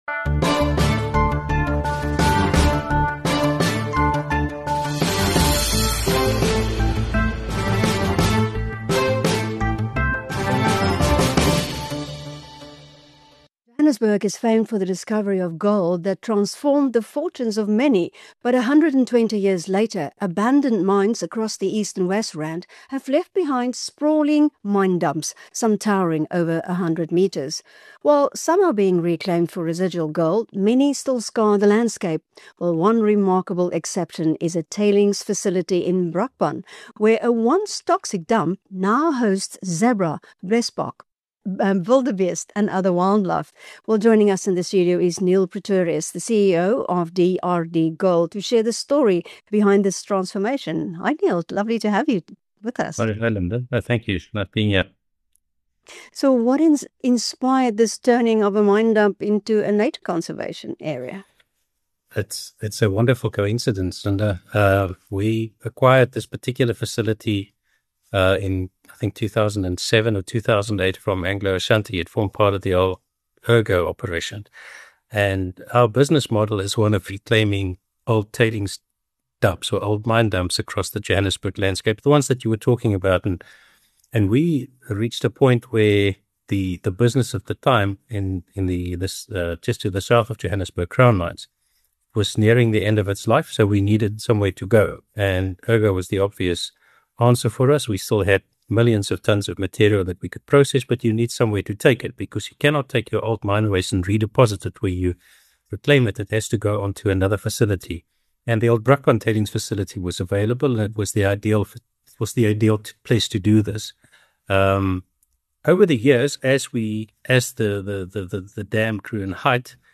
But at the Brakpan tailings facility, wildlife is making a comeback. Mining company DRDGold has rehabilitated the site by cladding mine waste with soil, allowing natural vegetation to return spontaneously. This, in turn, has attracted wildlife back to the area—prompting the company to reintroduce zebras, blesbok, and wildebeest. In an interview with BizNews